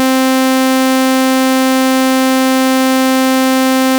Band Limited Saw Wave
Here’s how a band limited saw wave looks and sounds compared to a non band limited saw wave, like the ones we created in the last chapter.
saw.wav